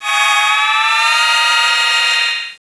1 channel
shieldup.wav